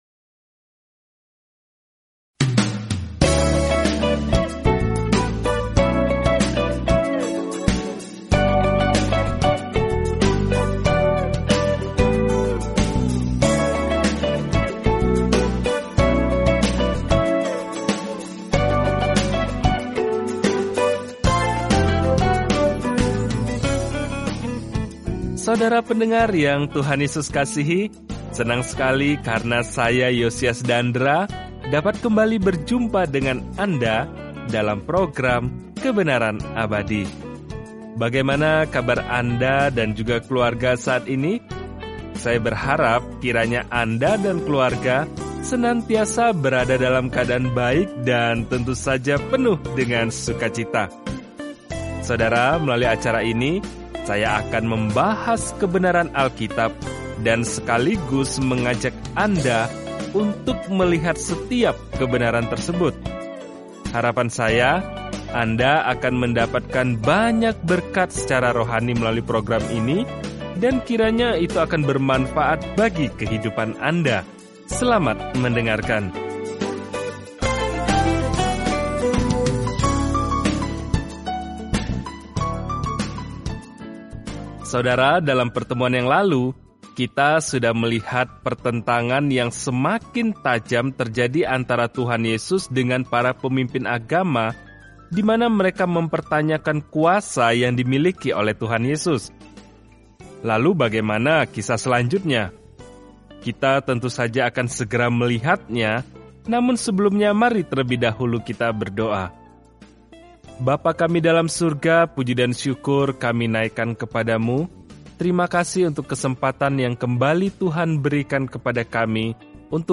Firman Tuhan, Alkitab Markus 12 Hari 13 Mulai Rencana ini Hari 15 Tentang Rencana ini Injil Markus yang lebih singkat menggambarkan pelayanan Yesus Kristus di bumi sebagai Hamba dan Anak Manusia yang menderita. Jelajahi Markus setiap hari sambil mendengarkan studi audio dan membaca ayat-ayat tertentu dari firman Tuhan.